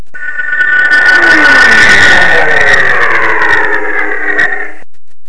N-1 flyby
nabooflyby.wav